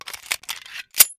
wpn_pistol10mm_reload.wav